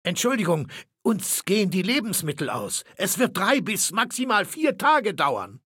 Maleold01_ms06_hello_000681b8.ogg (OGG-Mediendatei, Dateigröße: 50 KB.
Fallout 3: Audiodialoge